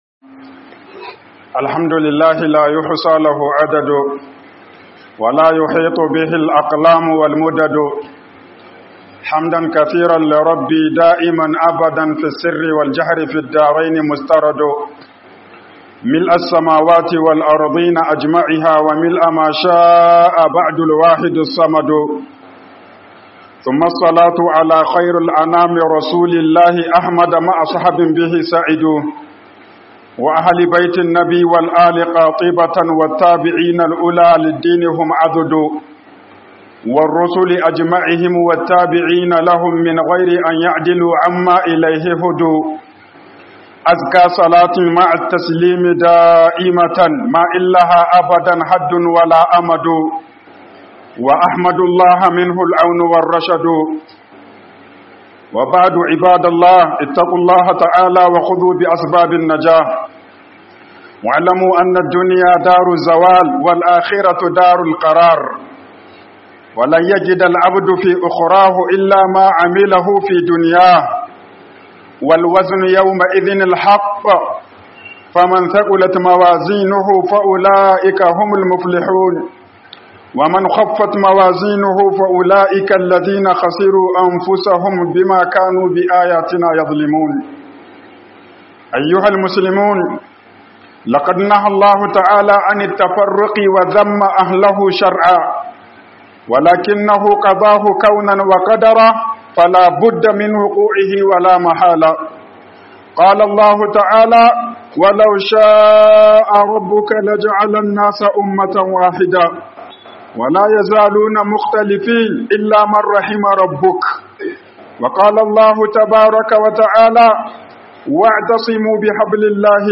HUƊUBOBIN JUMA'A